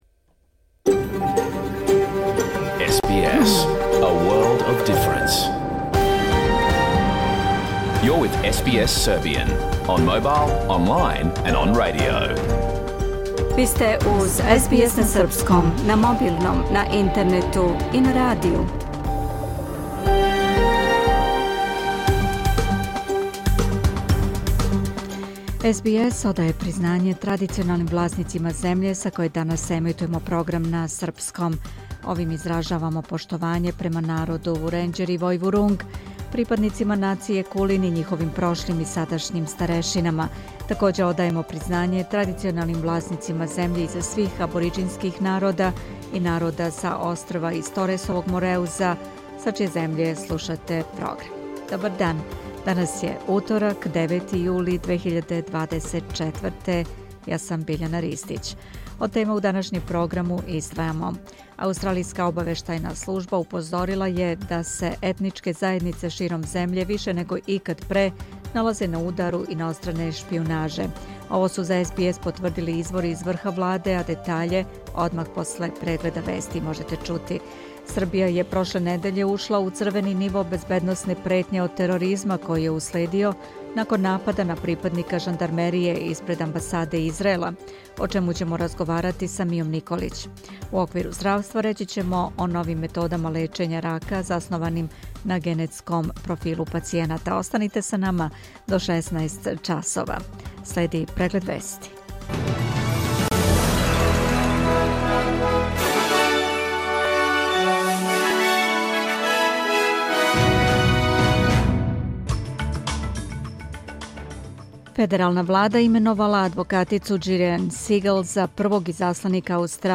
Програм емитован уживо 9. јула 2024. године